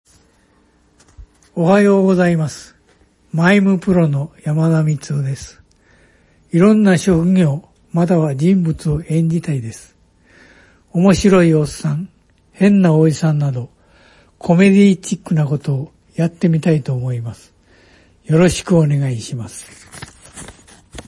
年齢 1948年⽣まれ（77歳） ⾝⻑・体重 174cm・62.5kg サイズ B/89 W/85 H/91 S/26 出⾝地・⽅⾔ 滋賀県・関西弁 血液型・利き⼿ Ａ型・右手 趣味 野球 資格・免許 普通自動車免許（MT） ボイスサンプル 自己紹介